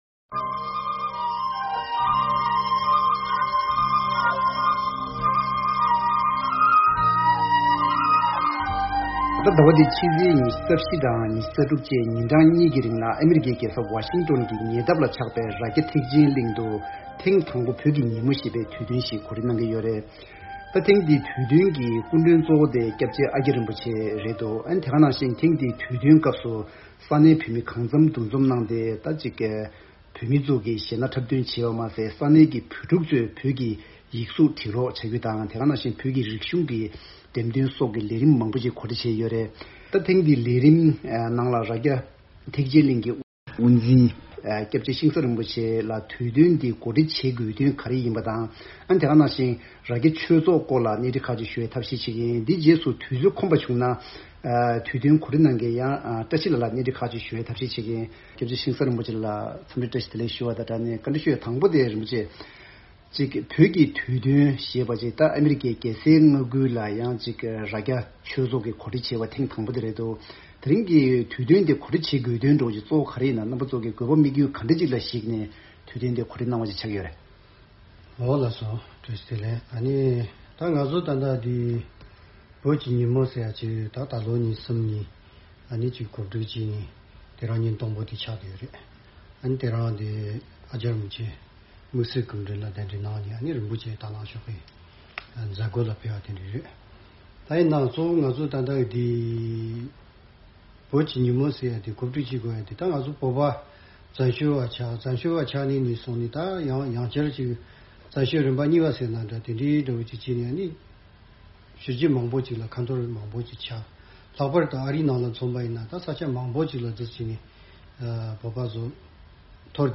རེས་གཟའ་སྤེན་པ་དང་ཉི་མ་བཅས་ཉིན་གཉིས་ཀྱི་རིང་ཨ་རིའི་རྒྱལ་ས་ཝ་ཤོན་ཊོན་གྱི་ཉེ་འཁྲིས་སུ་ཆགས་པའི་རྭ་རྒྱ་ཐེག་ཆེན་གླིང་དུ་བོད་ཀྱི་ཉིན་མོ་ཞེས་པ་གོ་སྒྲིག་གནང་ཡོད་པ་རེད། །ཐེངས་འདིའི་བགྲོ་གླེང་མདུན་ཅོག་ལས་རིམ་གྱིས་དུས་སྟོན་དེ་གོ་སྒྲིག་བྱེད་དགོས་དོན་དང། རྭ་རྒྱ་ཆོས་ཚོགས་ཀྱི་རྒྱབ་ལྗོངས་བཅས་ངོ་སྤྲོད་ཞུ་ཡི་ཡིན།